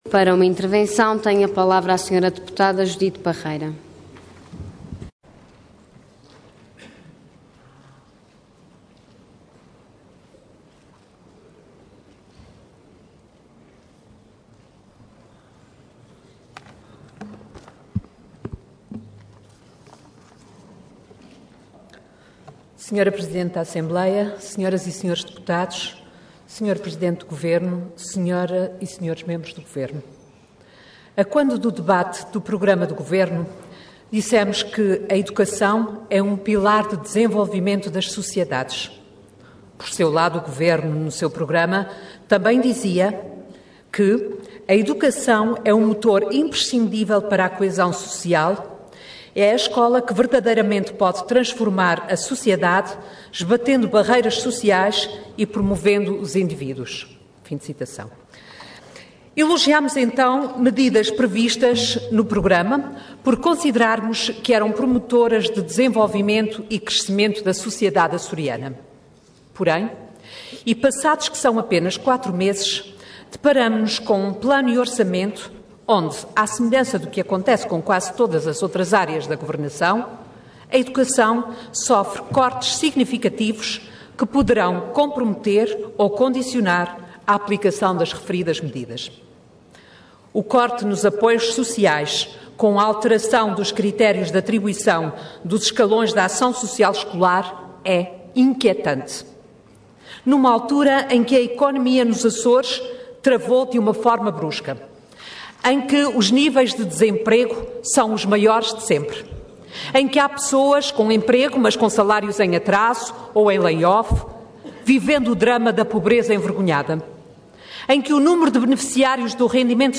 Detalhe de vídeo 20 de março de 2013 Download áudio Download vídeo Diário da Sessão Processo X Legislatura Plano e Orçamento para 2013 e OMP 2013 - 2016 Intervenção Intervenção de Tribuna Orador Judite Parreira Cargo Deputada Entidade PS